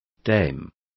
Complete with pronunciation of the translation of dames.